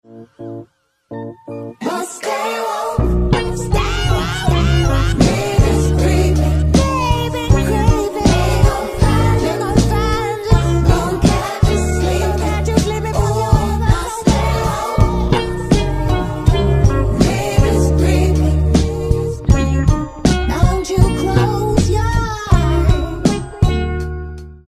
• Качество: 320, Stereo
мужской голос
спокойные
RnB
расслабляющие
соул